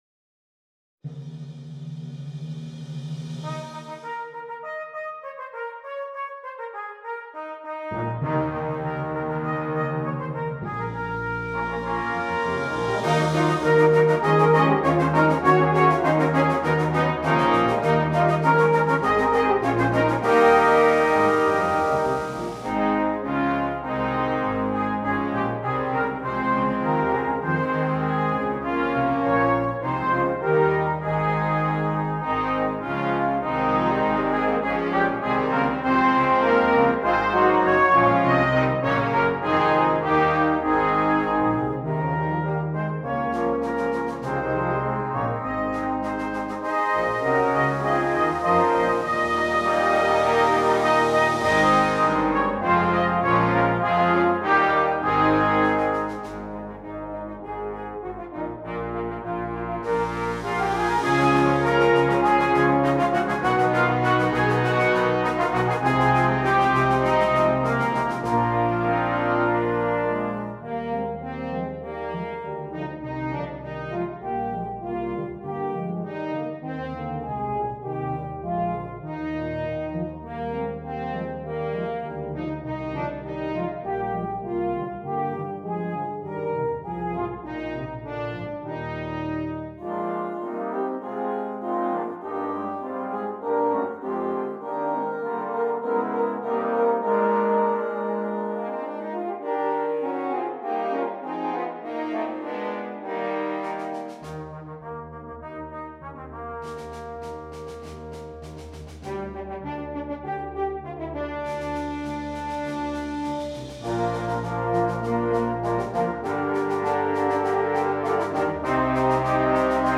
Brass Band
This is an exciting piece based on the Welsh hymn